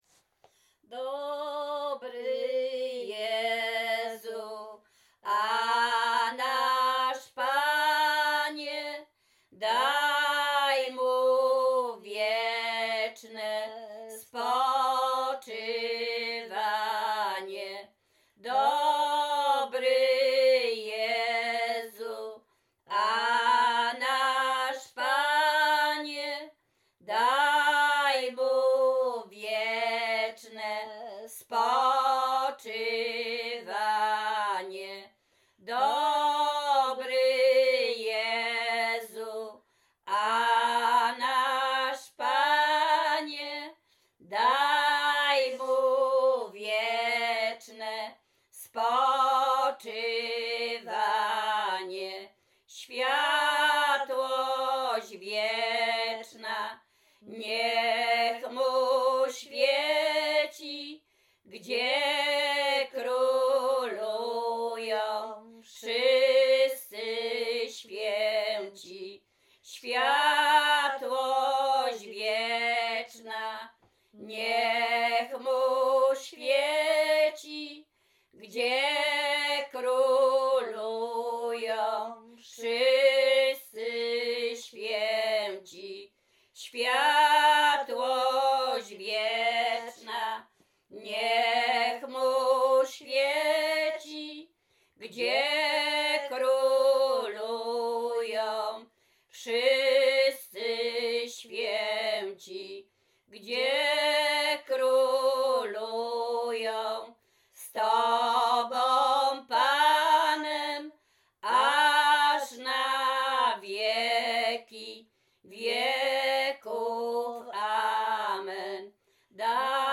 Śpiewaczki z Czerchowa
województwo łódzkie, powiat zgierski, gmina Ozorków, wieś Czerchów
Pogrzebowa